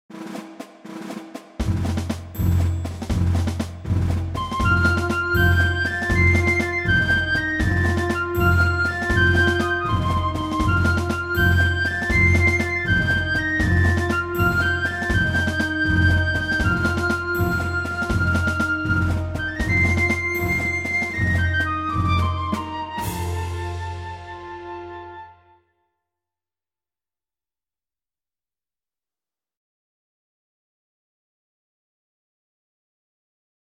Accomp